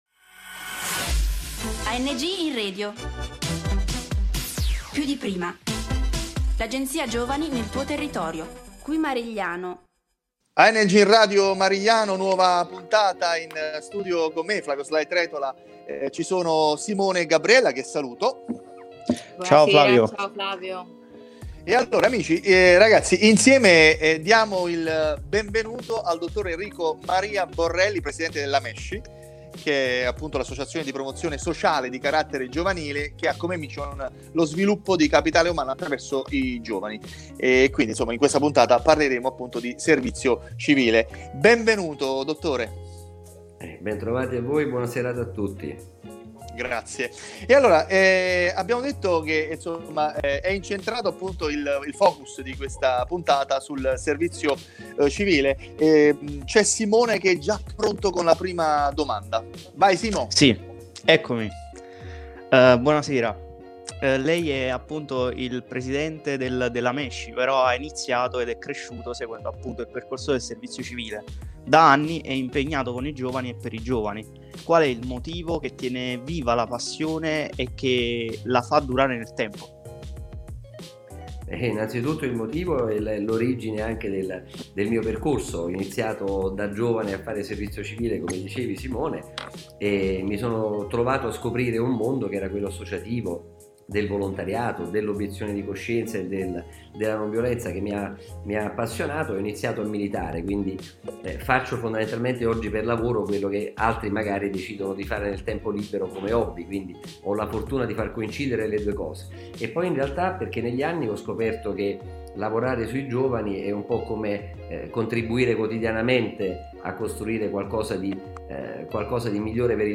Numerosi gli spunti di riflessione offerti dal bellissimo confronto di questa puntata. Come sempre in studio